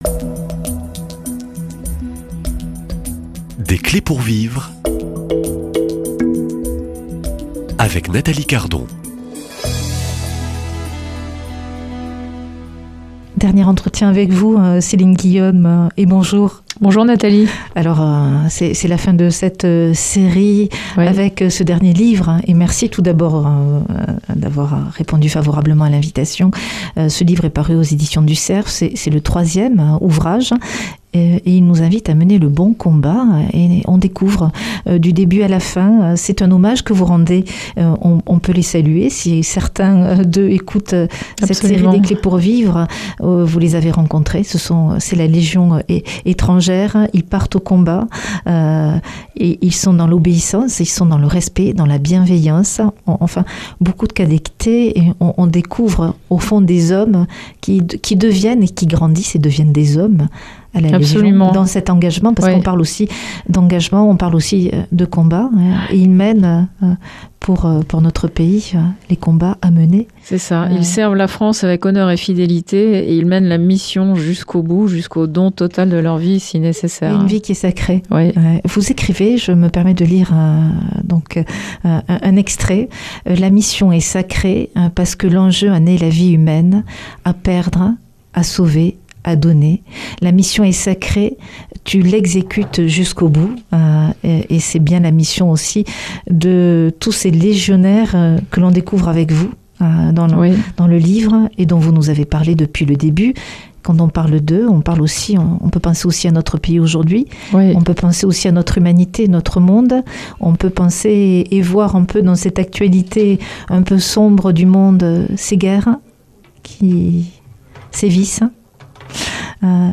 Invitée